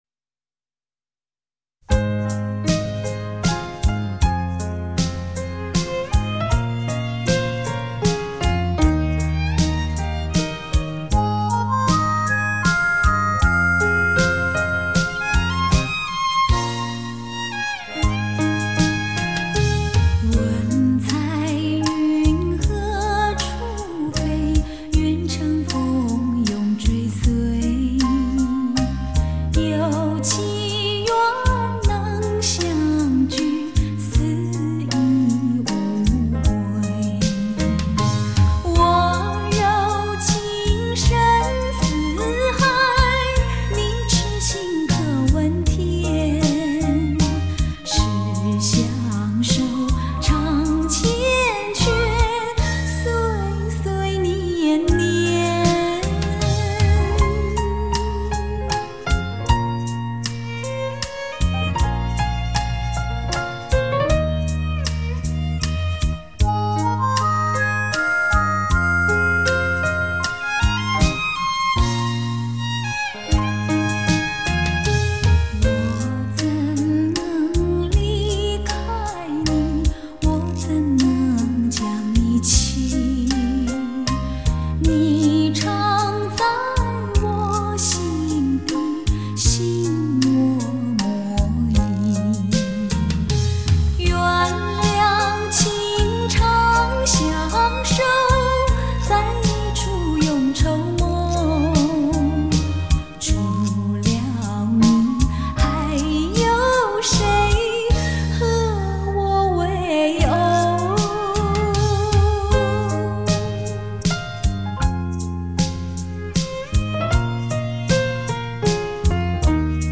幽雅的乐曲 甜润的歌声 回荡在你我心间
华尔兹